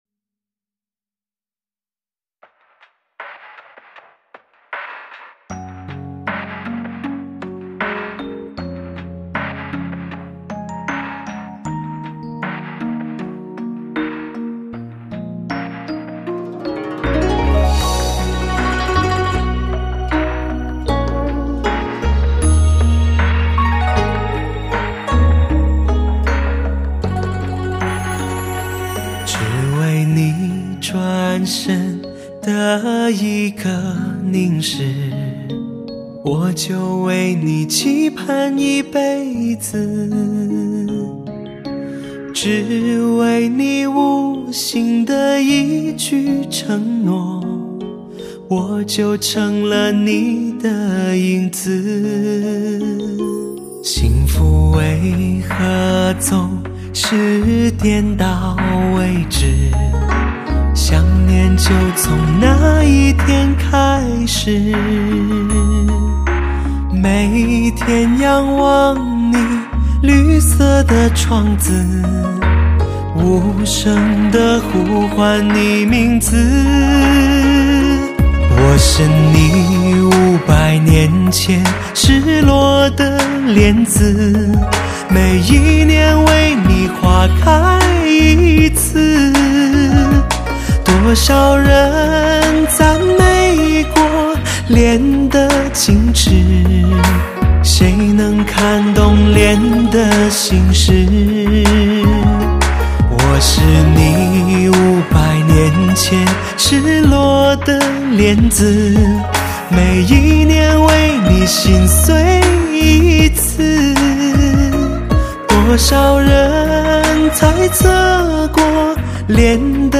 类型: 汽车音乐
王者之选，极致音色版，高品位制作，全音乐的感受。